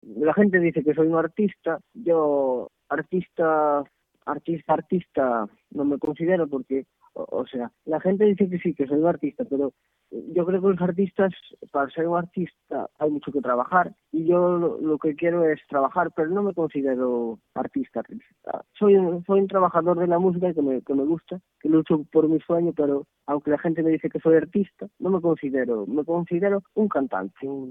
Así que el sueño se materializa: “mi pasión es la música y voy a luchar por conseguirlo. Luchando, luchando... aquí estoy ahora formato MP3 audio(0,18 MB), comenta risueño, al otro lado del hilo telefónico, dedicándonos un alto en el camino de su gira para compartir con nosotros emociones, sentimientos a flor de piel y el deseo, que atisba cada vez más cercano, de alcanzar la gloria del artista con mayúsculas.